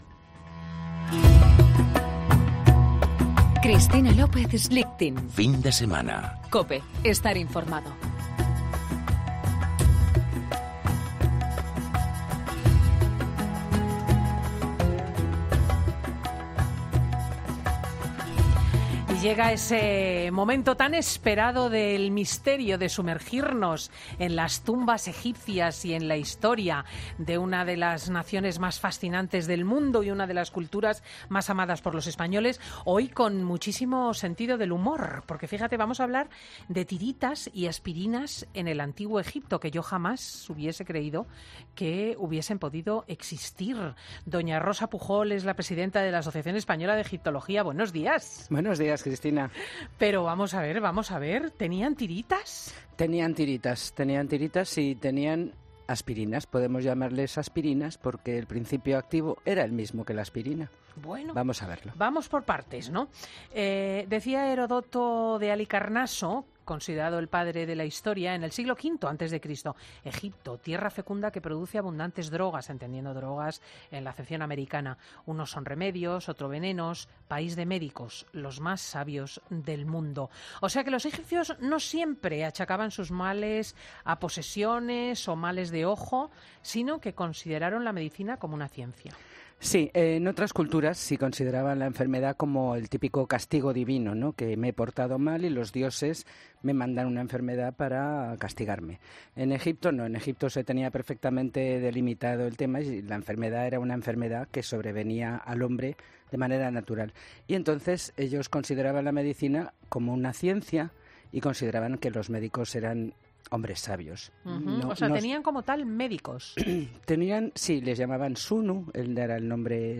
es un magazine que se emite en COPE